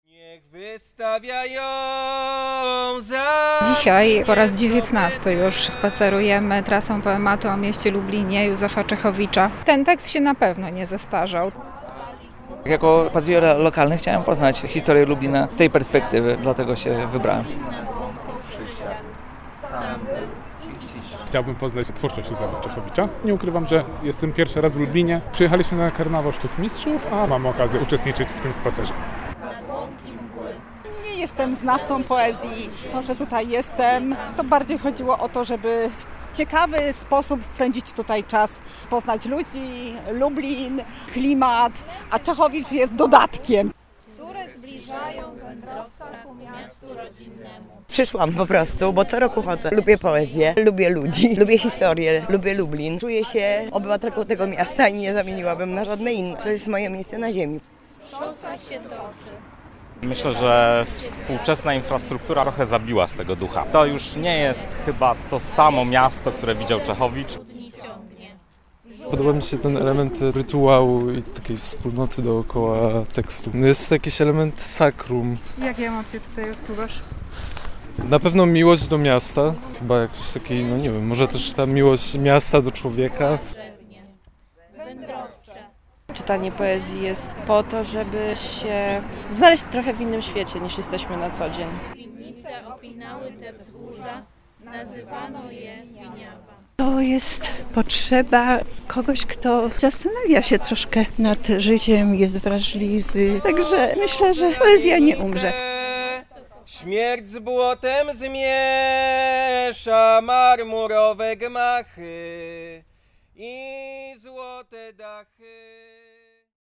Na poetyckim szlaku była również nasza reporterka: